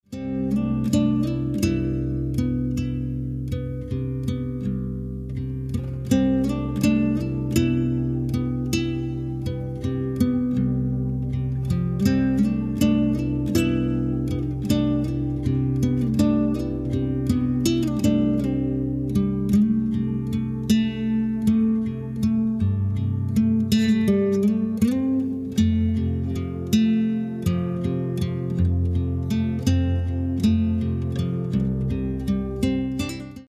contemplative soaking project
classical guitar
virtual cellos, orchestrations and other sounds